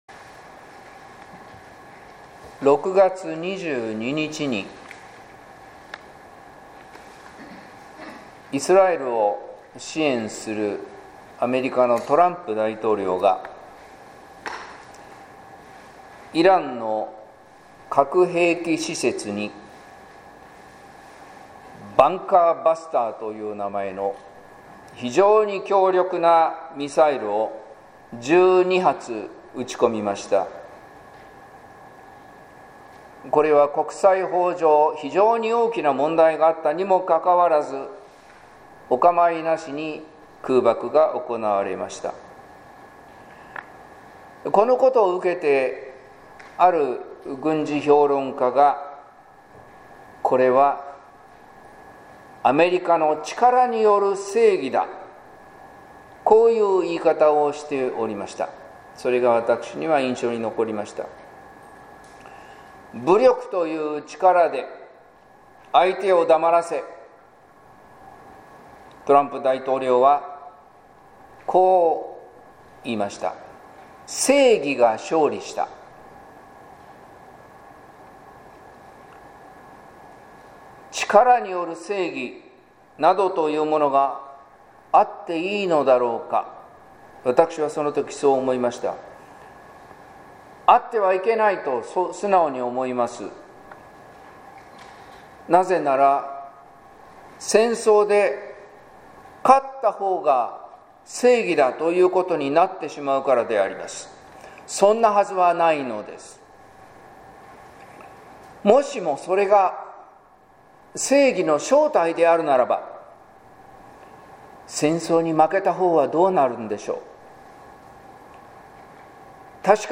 説教「イエスの教えコツコツと」（音声版）